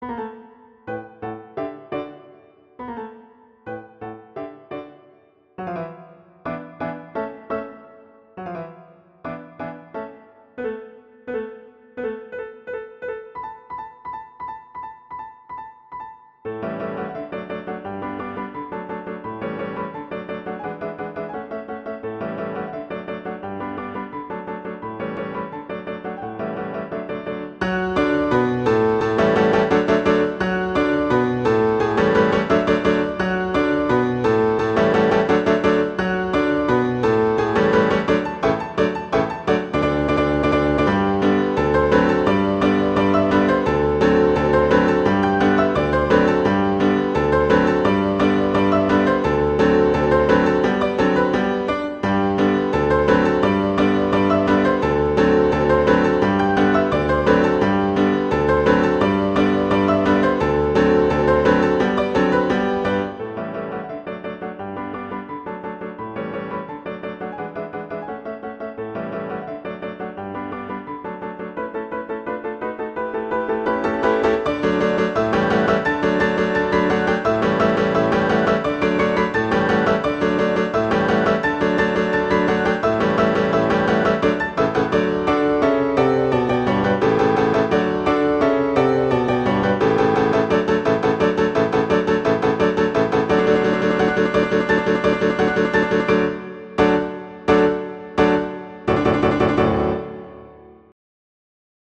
classical
B minor
♩=172 BPM (real metronome 168 BPM)
D2-D7